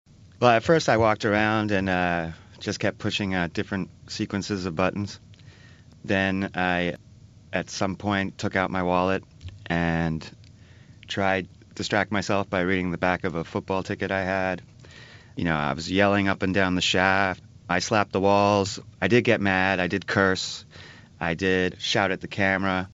【英音模仿秀】电梯被困之谜 听力文件下载—在线英语听力室